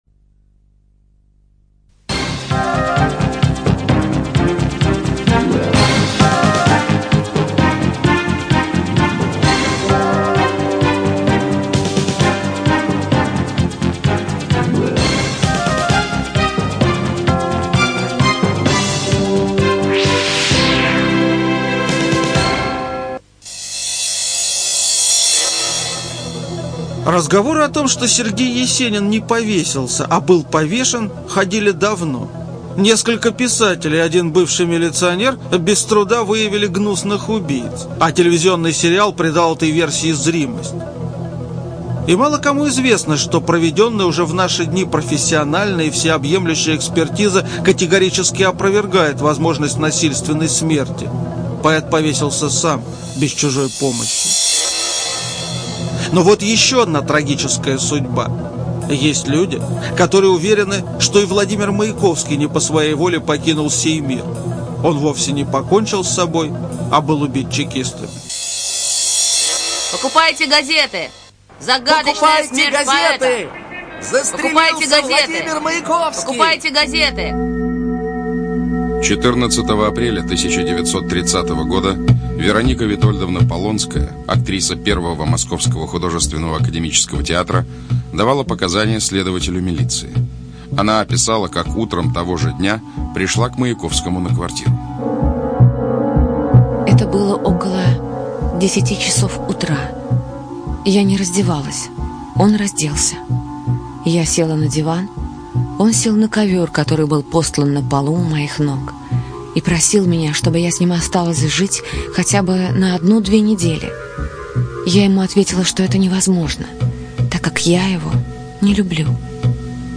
ЖанрТелевизионные программы